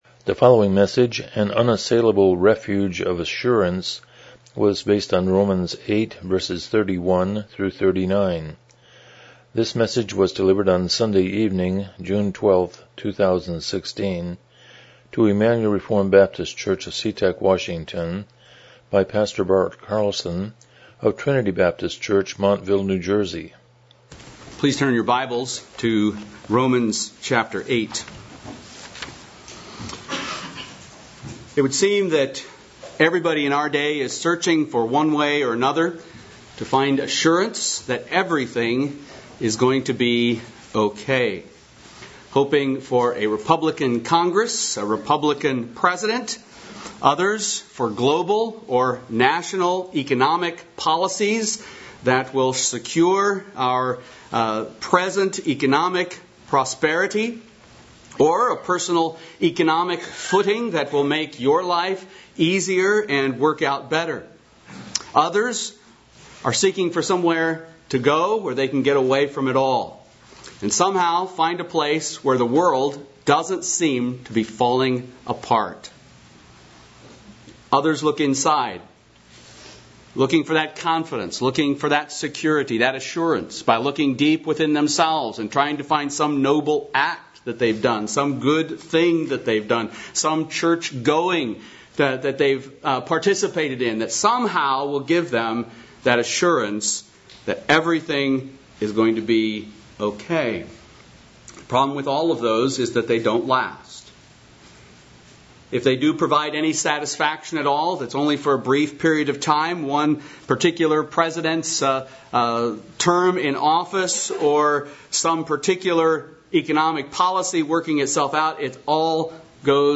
Miscellaneous Passage: Romans 8:31-39 Service Type: Evening Worship « The Gospel According to David